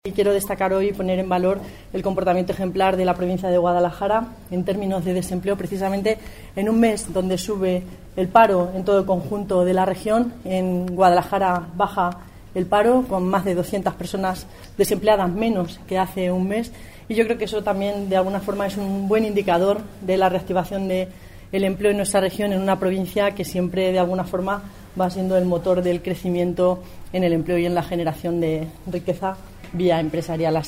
La consejera de Economía, Empresas y Empleo valora los datos del paro registrado en la provincia de Guadalajara.